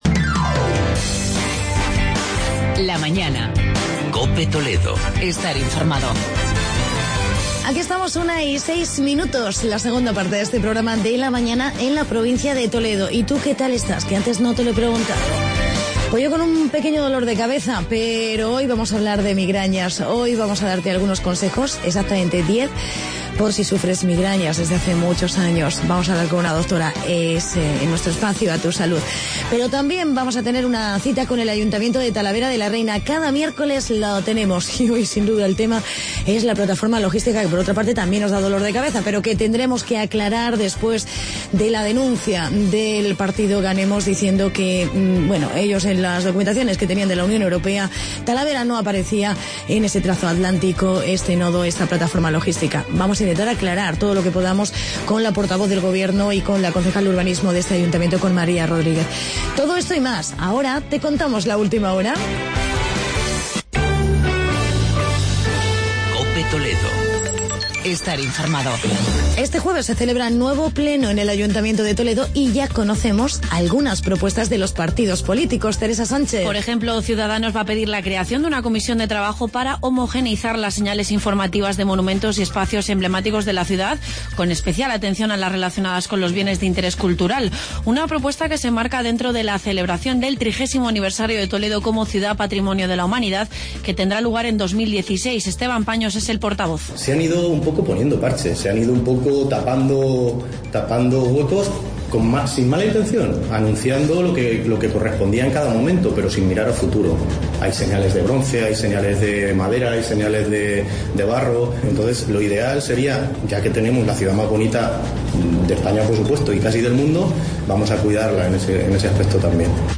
Entrevista con la concejal talavera María Rodríguez sobre Nodo Logístico y en "a Tu Salud" hablamos sobre la migraña.